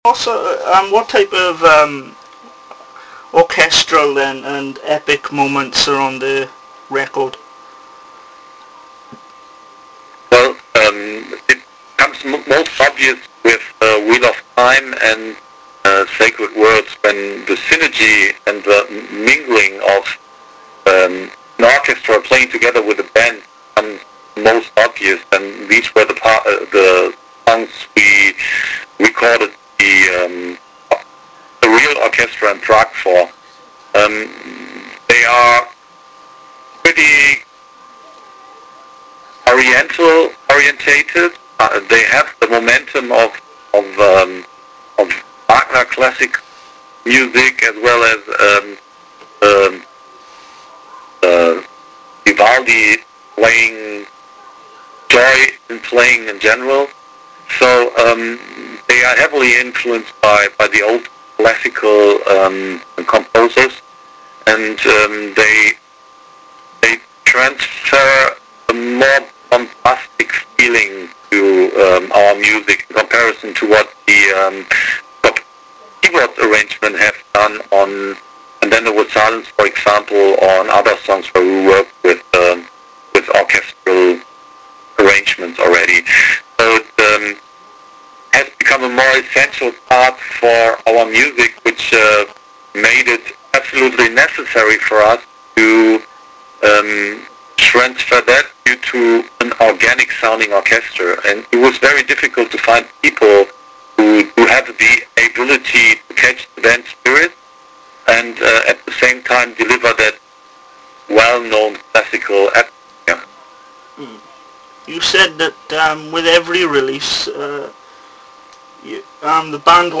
You can now listen to the chat in two parts: part one and part two.